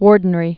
(wôrdn-rē)